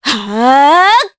Juno-Vox_Casting3_jp.wav